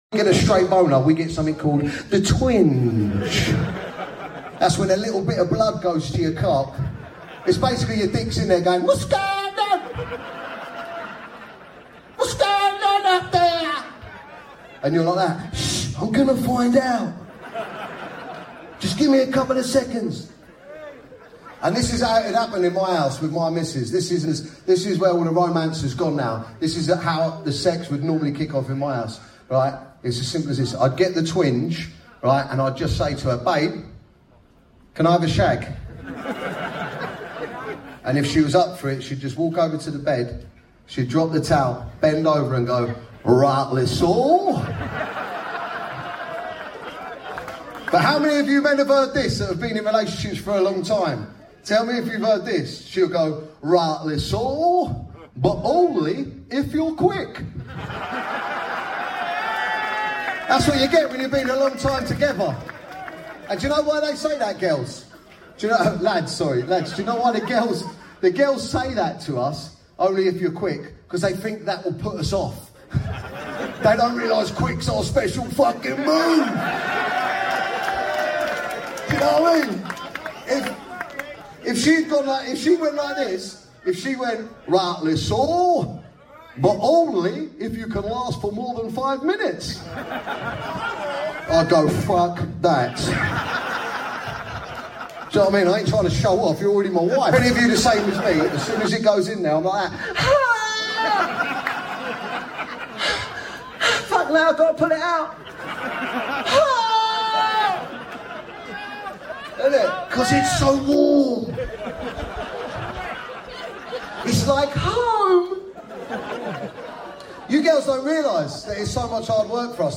BEDROOM BANTER 😏🤌🏻 Dapper Laughs aka Dan O'Reilly Headline Performance for M.A.T.E at Indigo O2. Finishing the show with the cherry on top, what a great and hilarious show great turnout all in support of men's mental health.